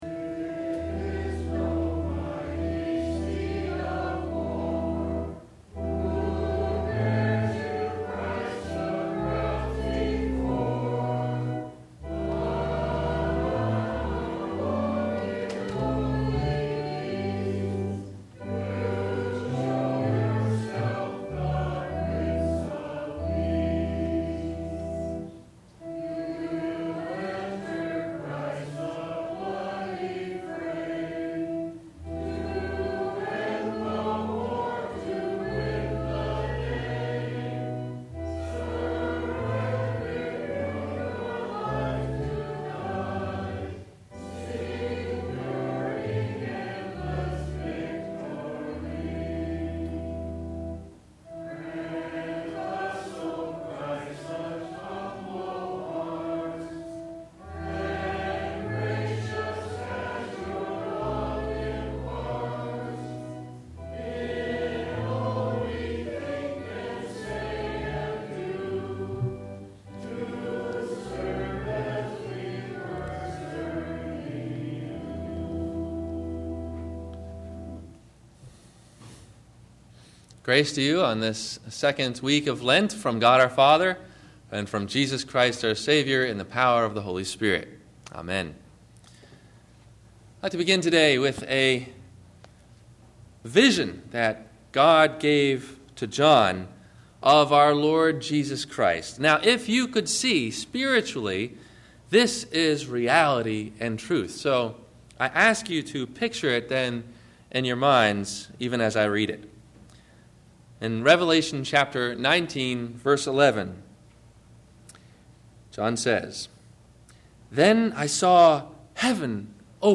Donkey – Wed. Lent – Sermon – February 13 2008